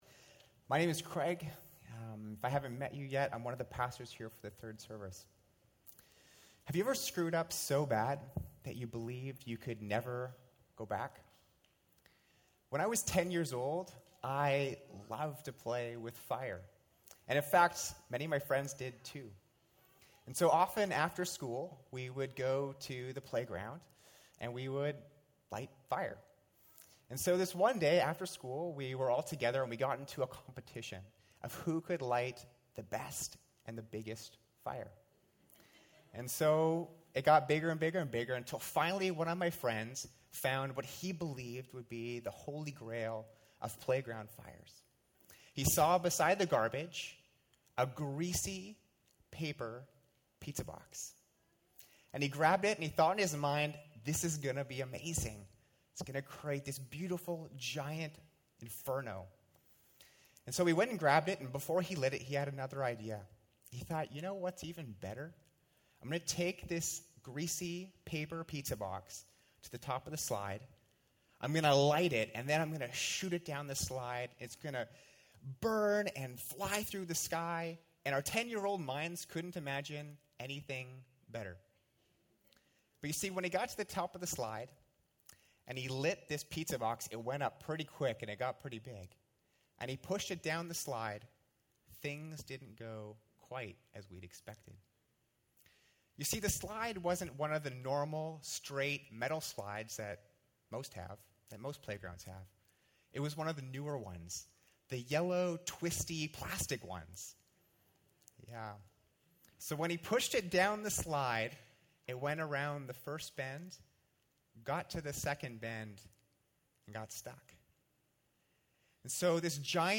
Episode from Tenth Church Sermons